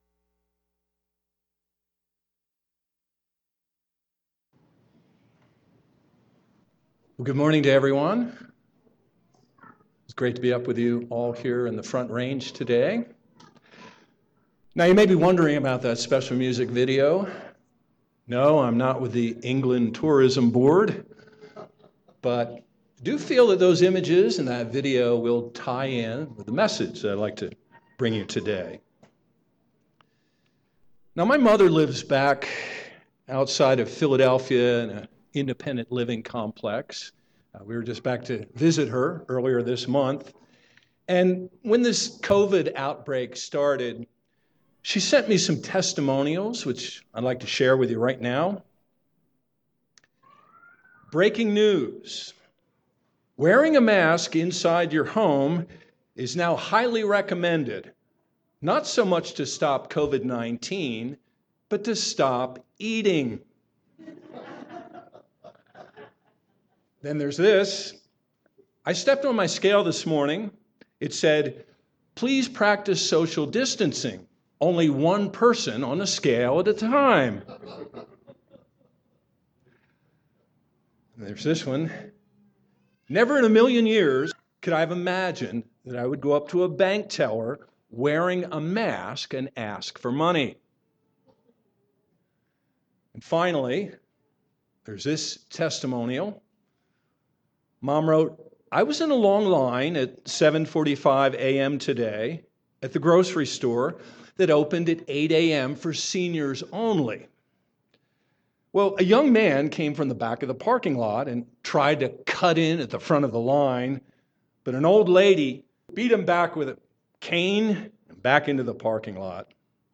Given in Colorado Springs, CO Denver, CO